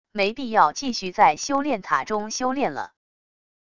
没必要继续在修炼塔中修炼了wav音频生成系统WAV Audio Player